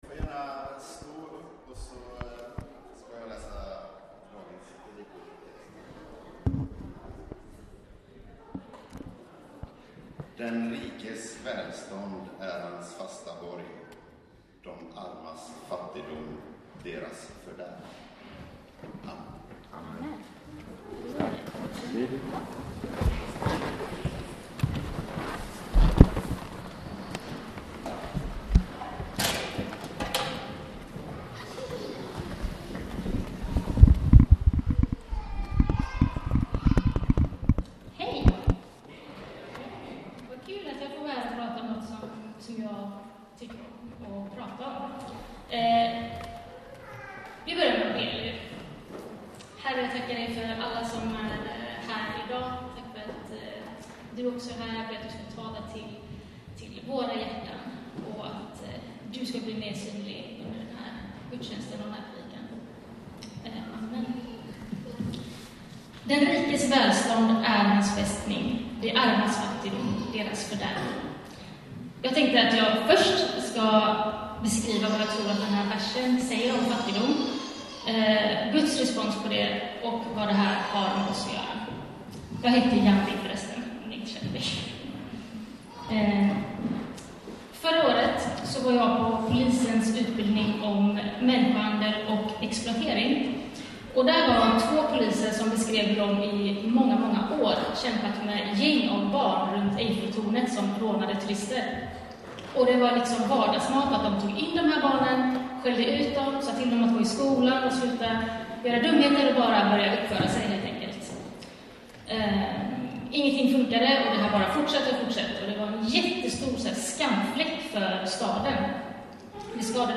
Sermons – CENTRO Podcast - Kyrka för staden: Del 4 Socialt ansvar | Free Listening on Podbean App